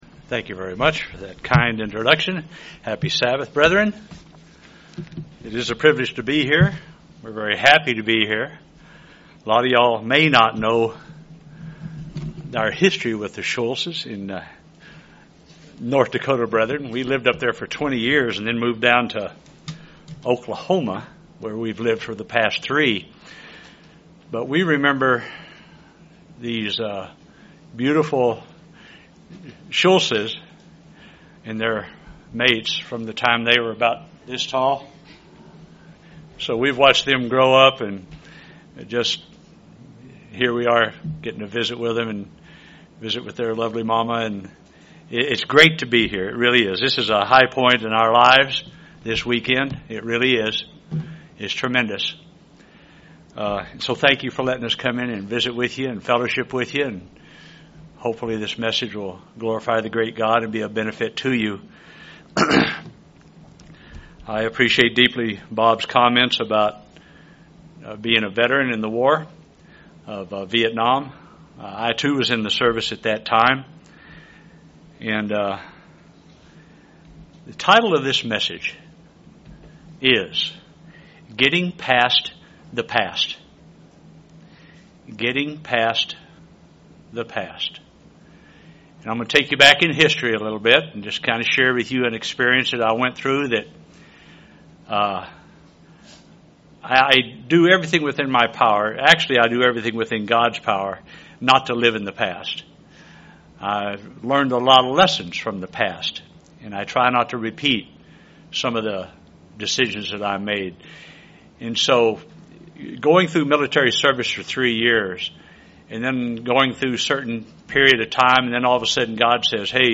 In this sermon, the speaker looks into the topic of forgiveness and reconciliation.
Given in Springfield, MO